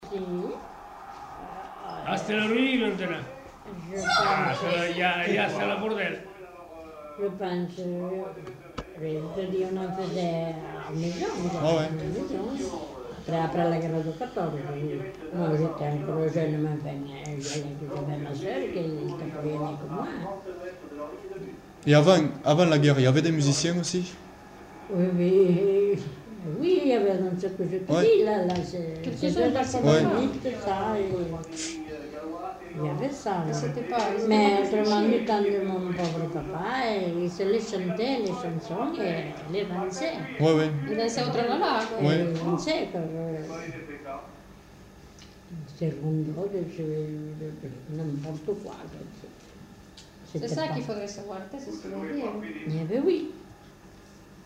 Lieu : Pavie
Genre : témoignage thématique